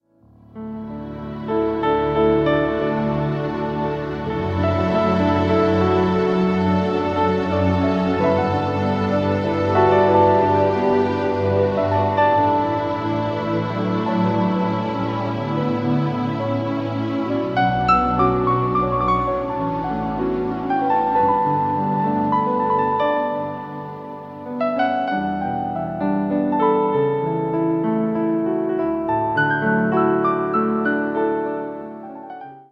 instrumental CD
birthed spontaneously during times of intimate worship